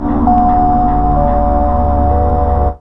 1907L SYNPAD.wav